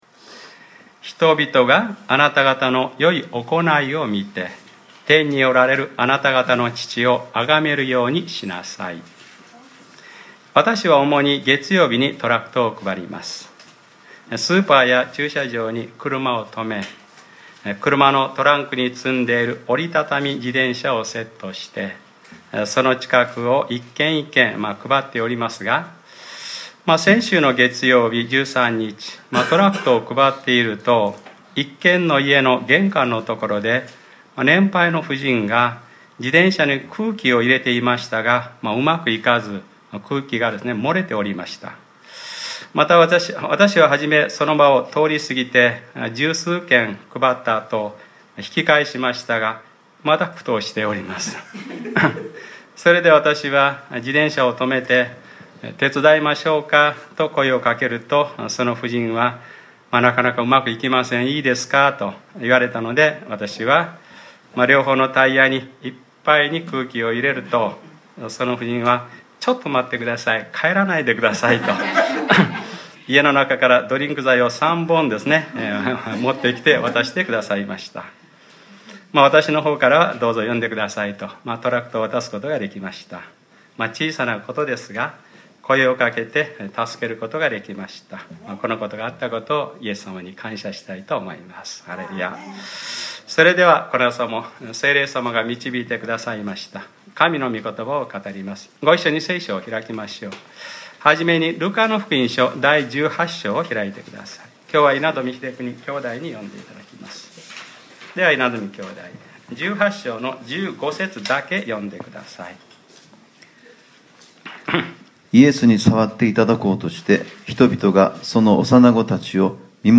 2015年07月19日（日）礼拝説教 『ルカｰ６４：子どものように神の国を受け入れる』